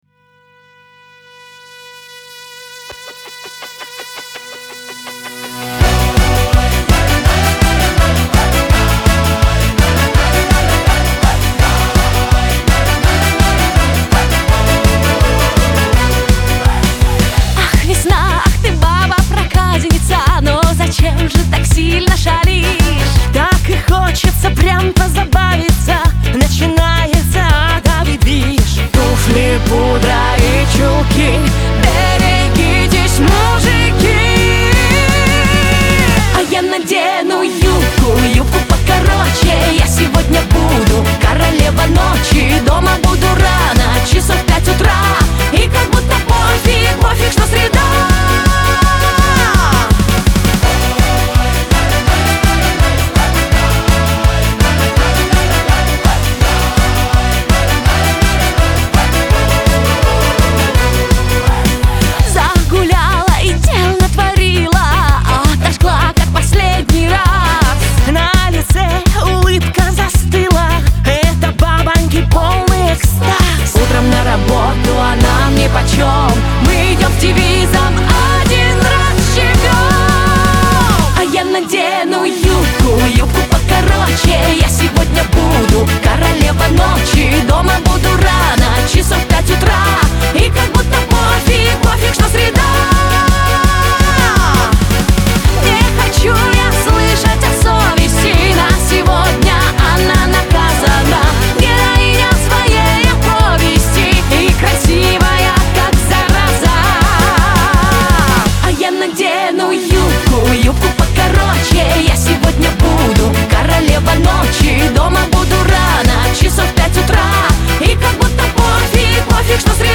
эстрада , pop
Веселая музыка , дуэт